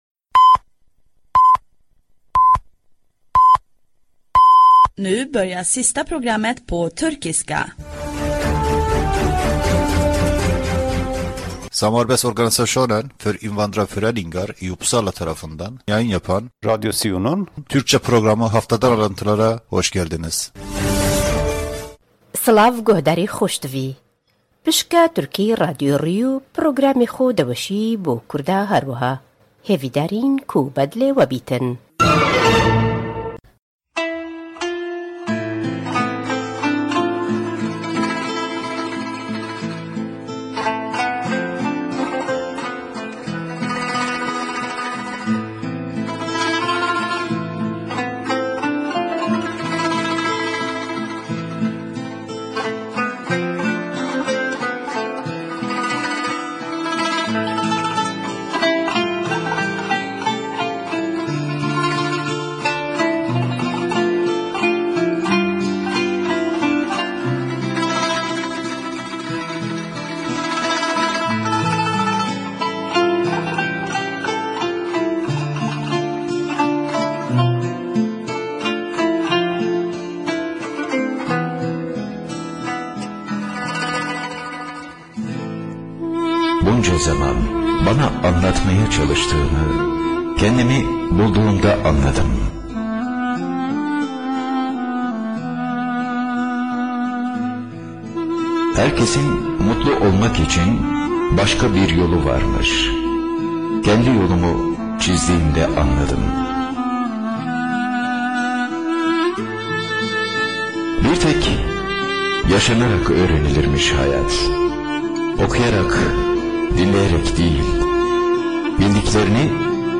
Radyo SİU bünyesinde yayın yapan bir programdır. Program; SIU´nun hafta içi faalıyelerıyle ilgili haberler ile lokal haberler ve İsvec genelinden haberleri içerir.